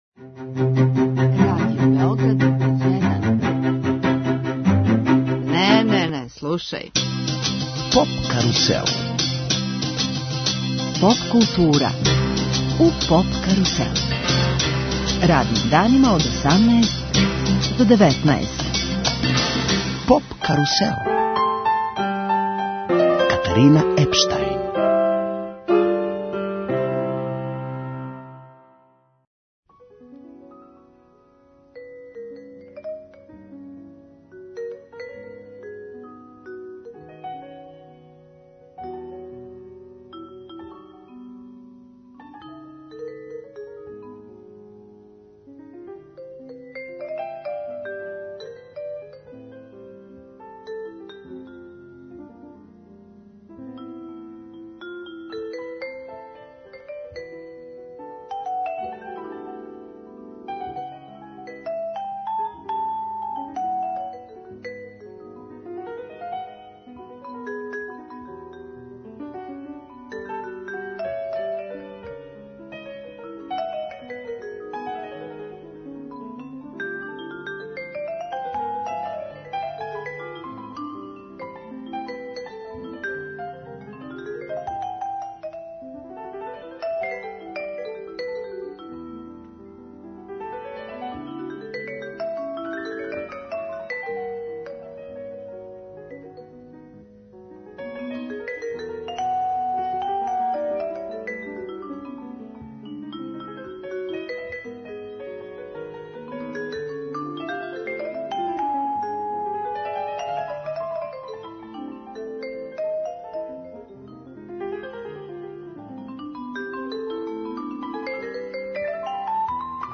Гост емисије је прослављени руски џез саксофониста, Олег Кирејев.